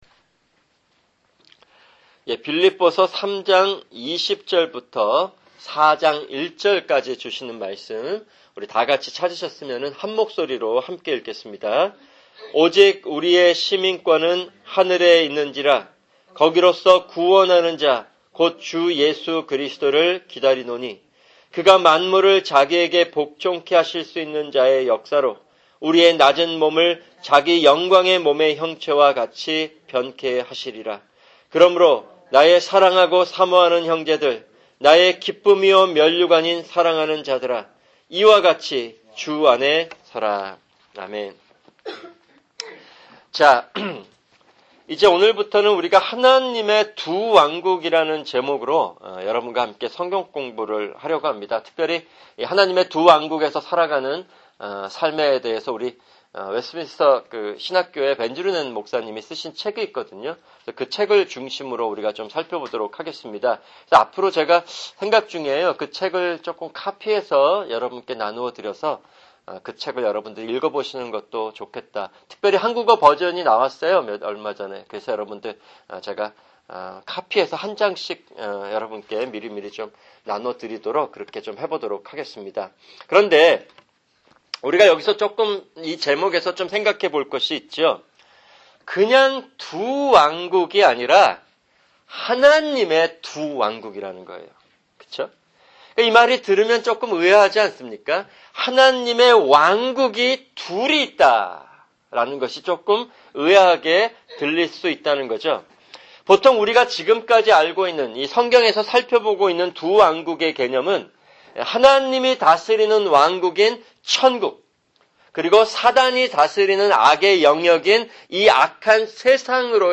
[금요 성경공부] 하나님의 두 왕국(1)